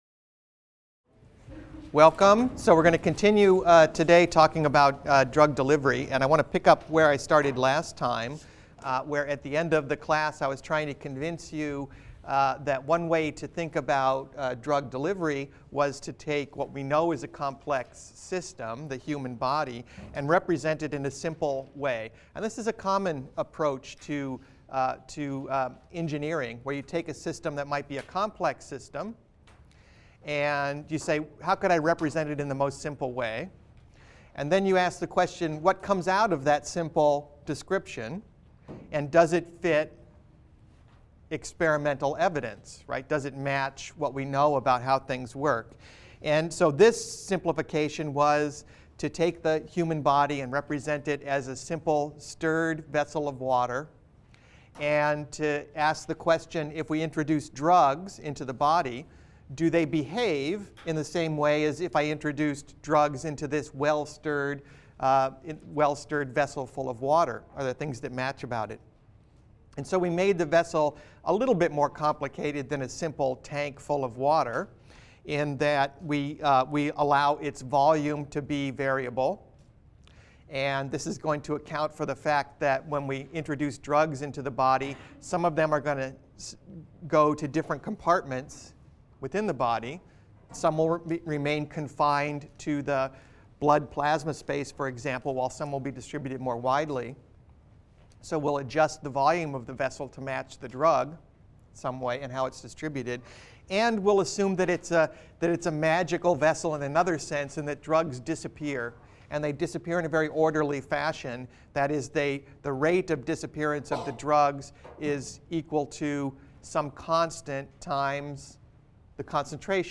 BENG 100 - Lecture 12 - Biomolecular Engineering: General Concepts (cont.) | Open Yale Courses